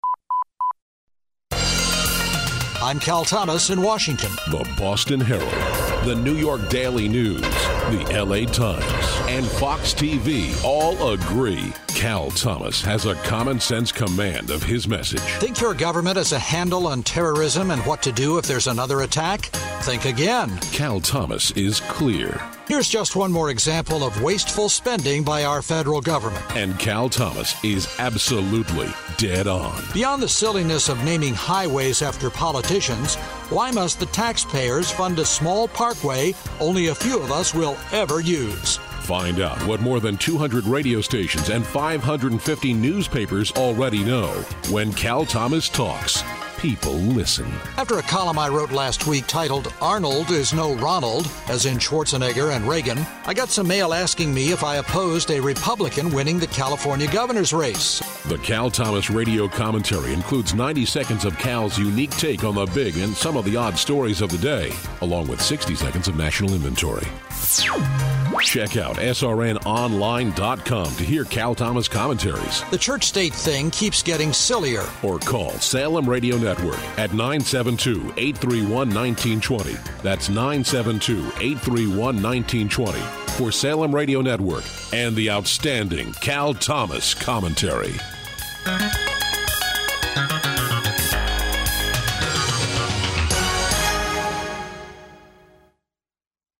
Show Demo
His no-nonsense style and uncanny ability to cut through the spin has also earned him the reputation as one of the country’s most influential radio commentators.
Simply put, The Cal Thomas Commentary is a 90-second daily dose of Cal Thomas!